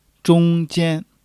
zhong1--jian1.mp3